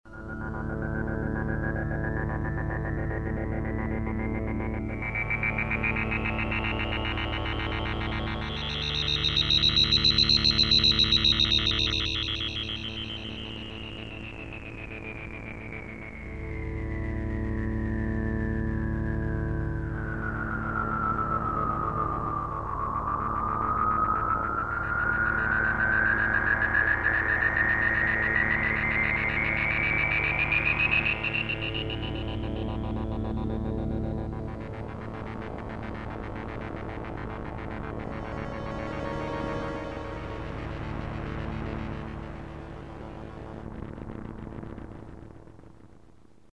16 Bit Digital Synthesizer
demo pad: 1 2 3 4 5 6